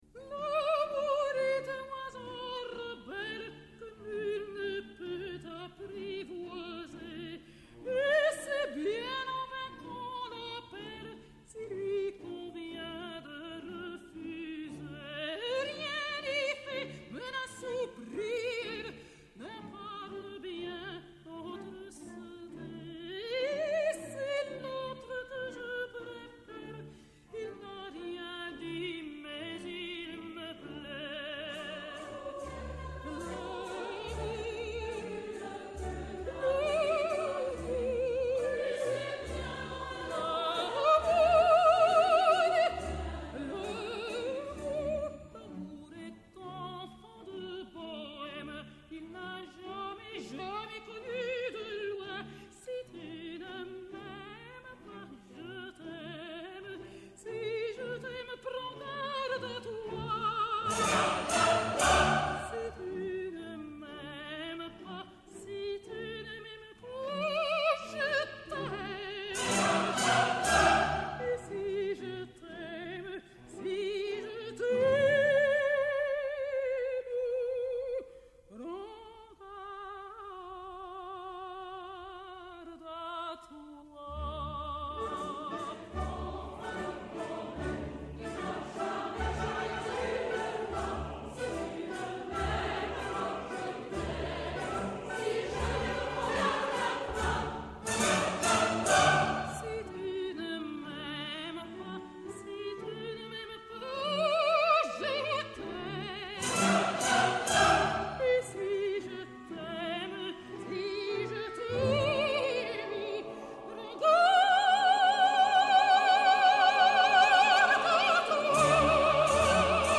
μια συζήτηση